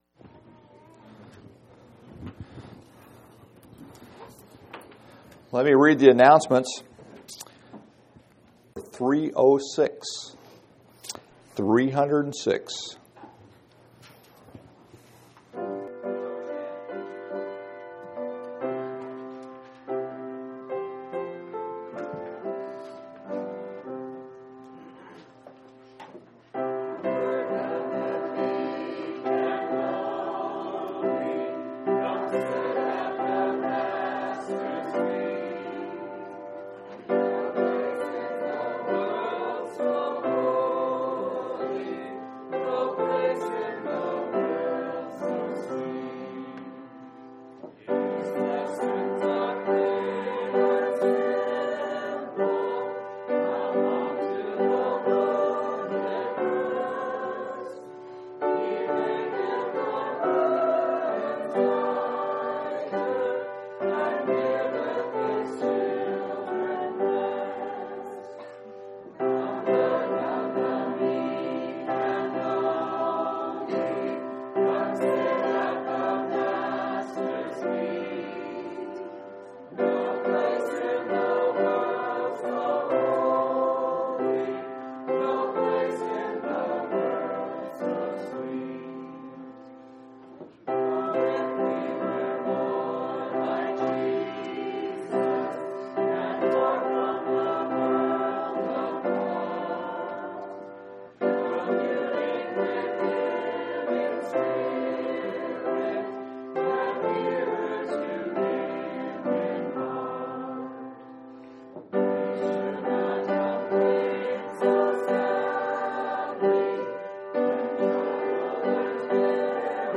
11/23/2003 Location: Phoenix Local Event